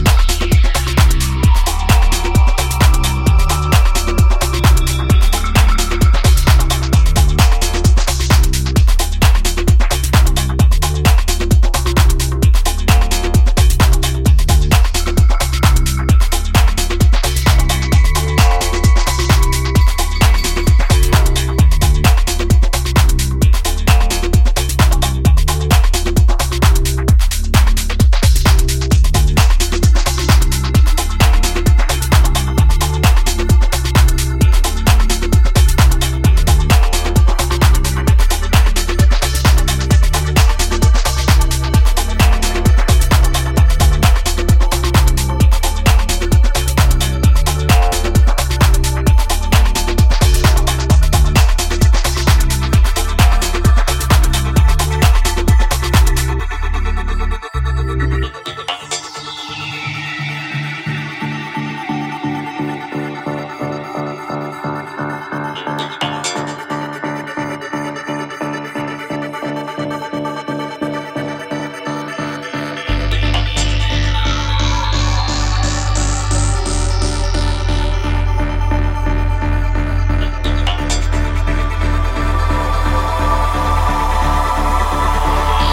anthemic two-tracker